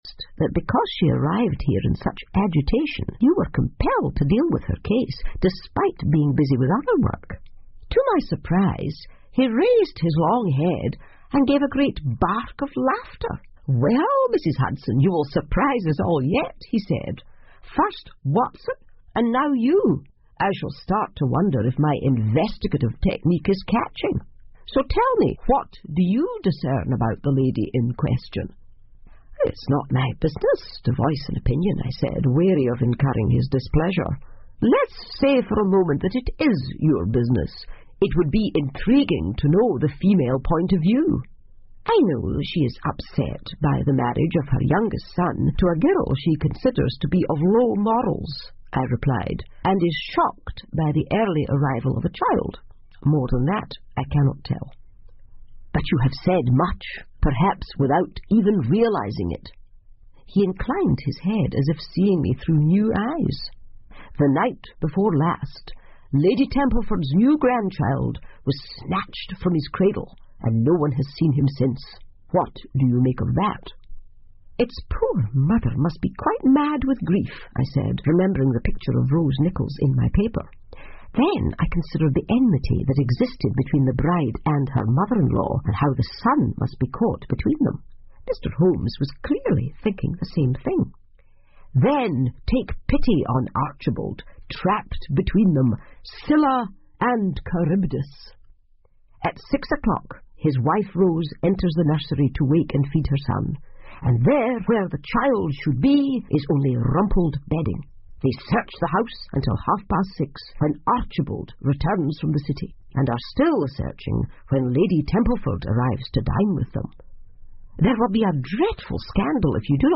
福尔摩斯广播剧 Cult-The Lady Downstairs 3 听力文件下载—在线英语听力室